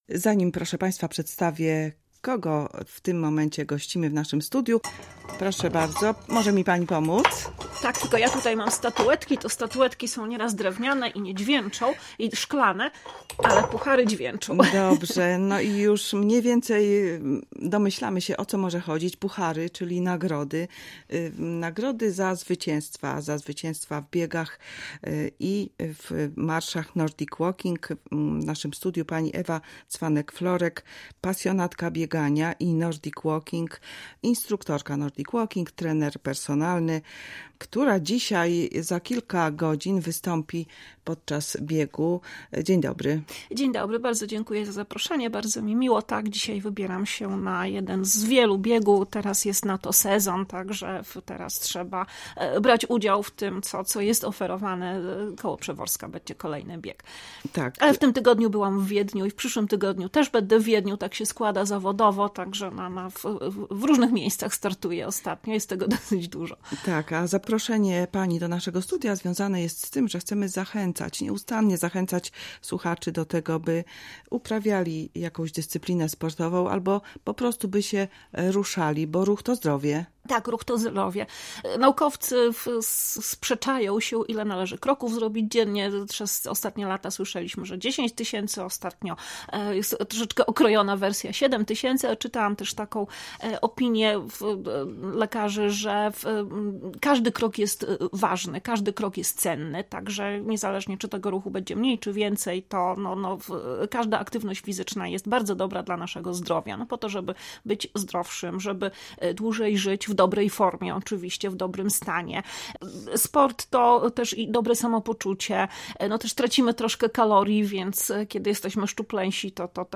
Audycje